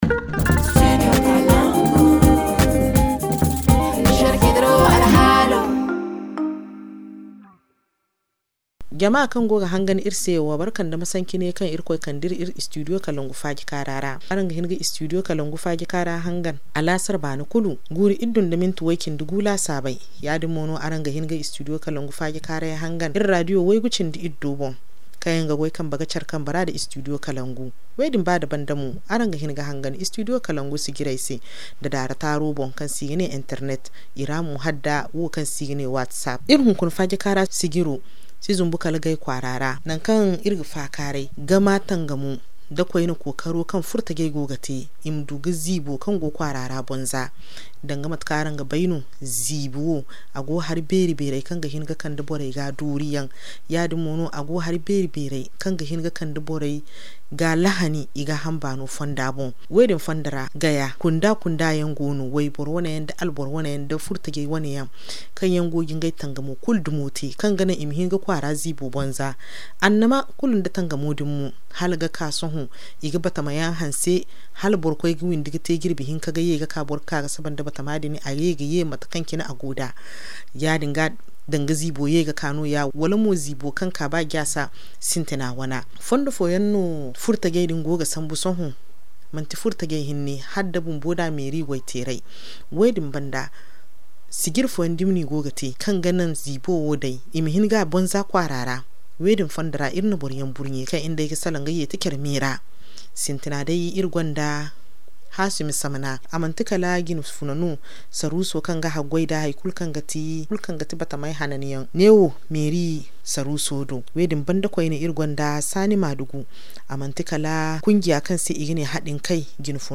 Les jeunes occupent une place centrale dans ce processus en œuvrant activement à l’amélioration de la qualité de vie en milieu urbain. Aujourd’hui, nous avons le plaisir d’accueillir trois invités qui partageront leurs expériences et expertises sur cette thématique, il s’agit de :